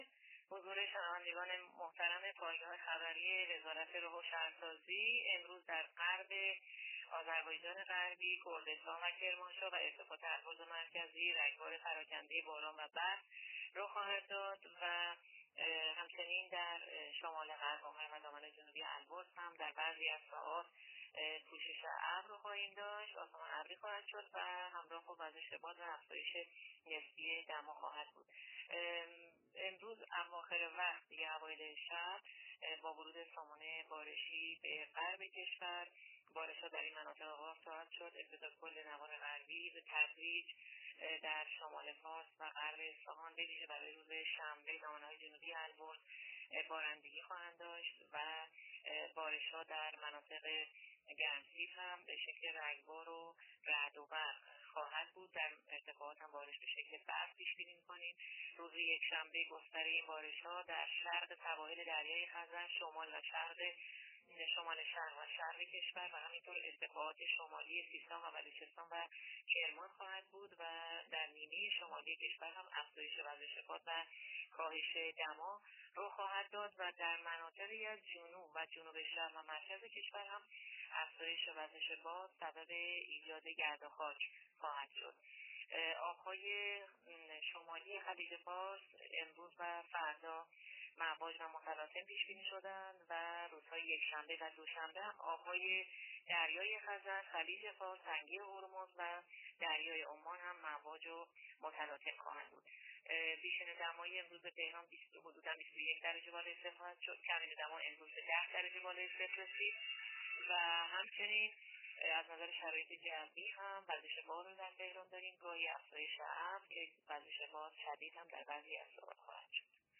گزارش رادیو اینترنتی از آخرین وضعیت آب و هوای ۲۴ بهمن؛